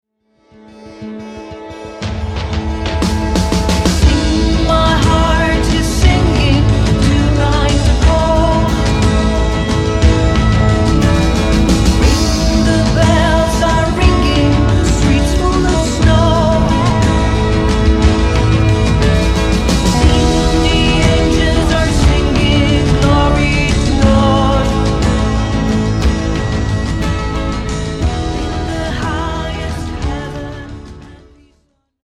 Chitarre